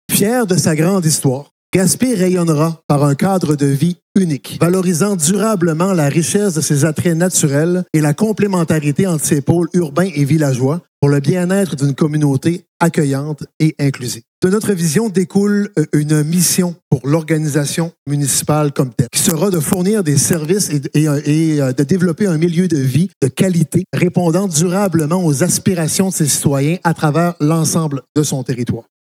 Le maire de Gaspé, Daniel Côté, présente la vision et la mission de Gaspé :